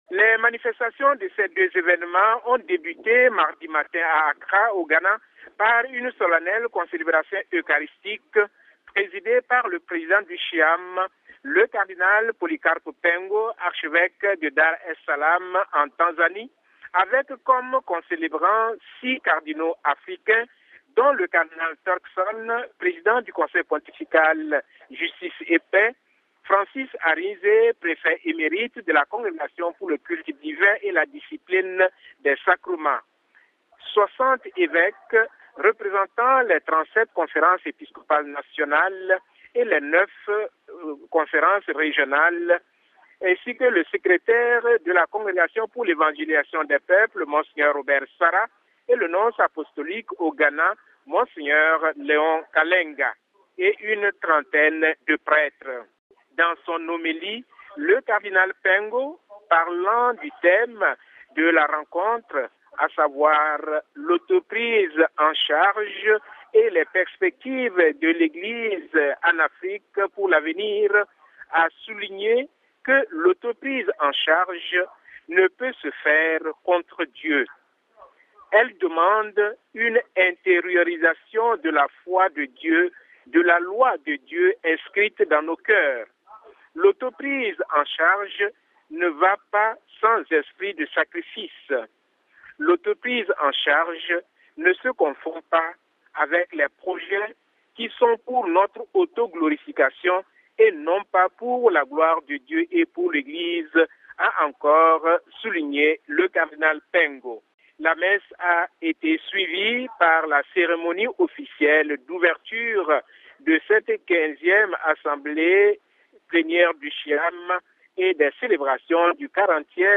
Mais pour plus de détails rejoignons à Accra